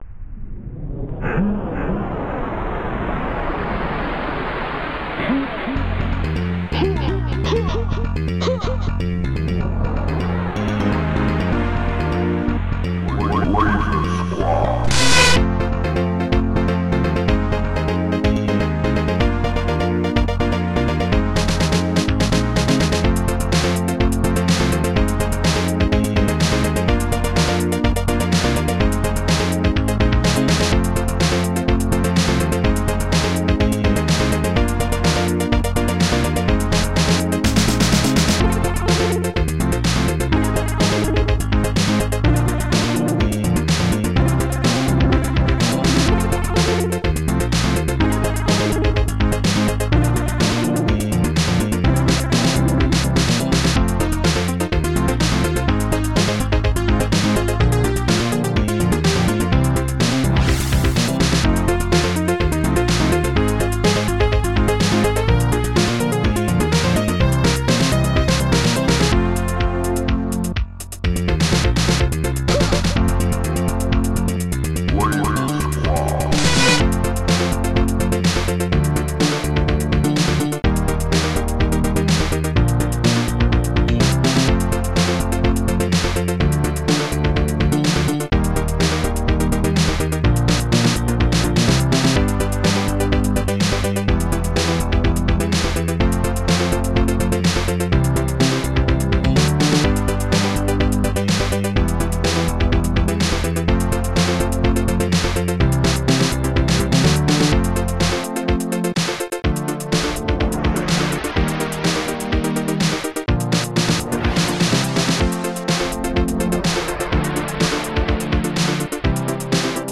Protracker Module  |  1990-06-26  |  155KB  |  2 channels  |  44,100 sample rate  |  2 minutes, 56 seconds
Protracker and family
chord-synth1-minor
chord-synth1-major
leadmellow-21
hi-hat closed 6
guitar pluck 5
thrash snare 16
leadsynth harsh 2
kick 34